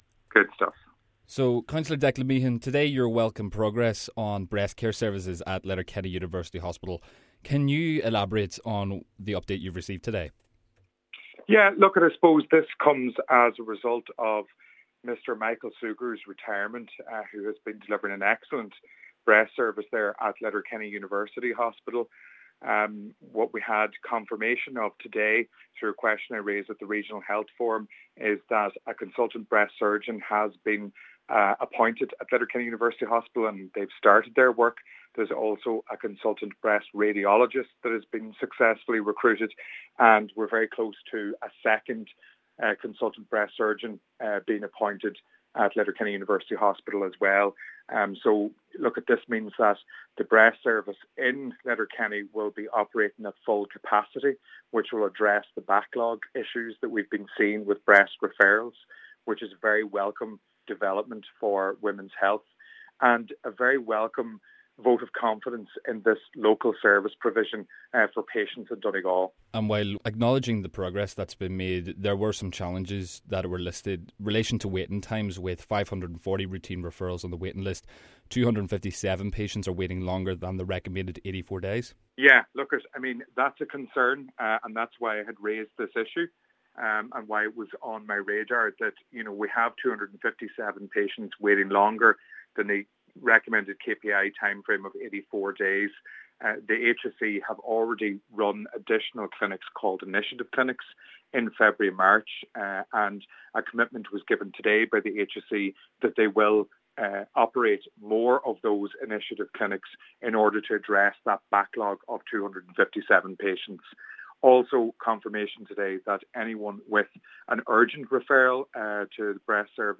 Cllr Declan Meehan raised the issue and has welcomed the work by the HSE: